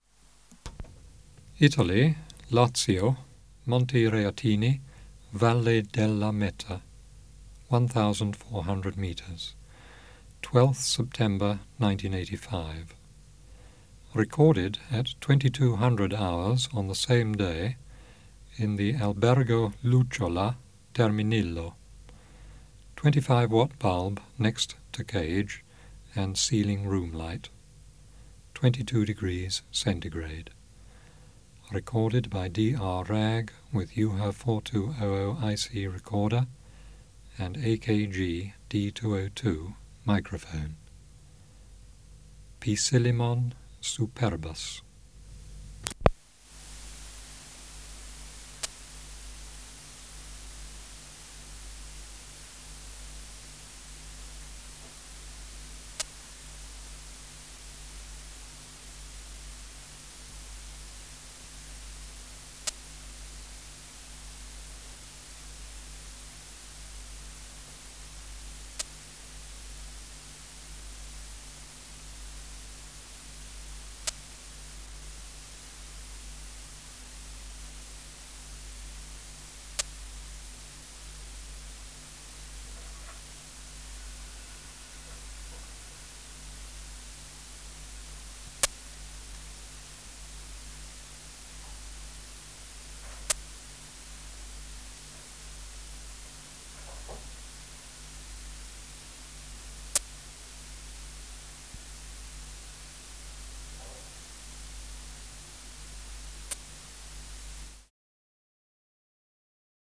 Project: Natural History Museum Sound Archive Species: Poecilimon (Poecilimon) jonicus superbus